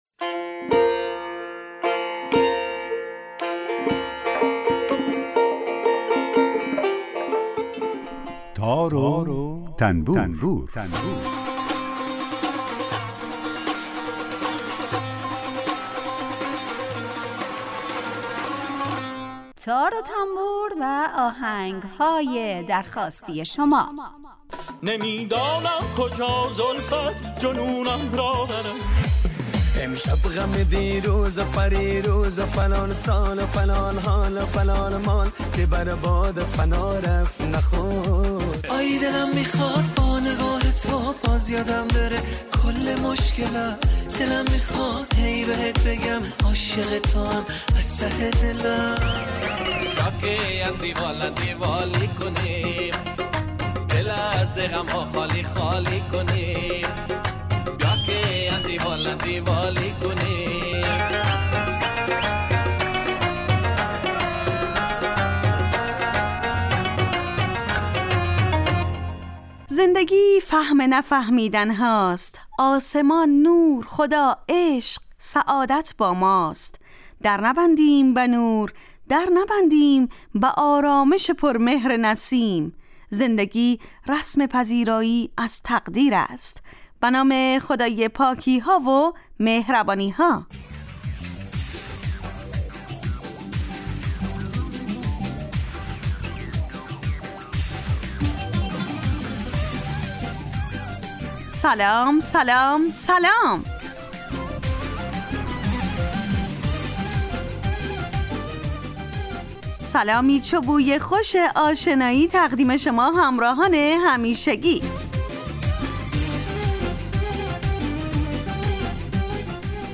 آهنگ های درخواستی